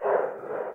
breath3gas.ogg